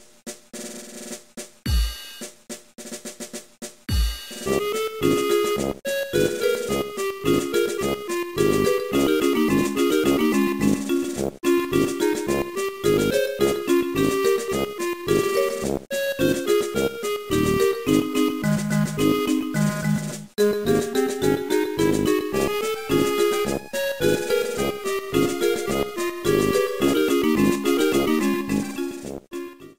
Clipped and applied fade-out with Audacity.